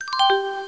chimes.mp3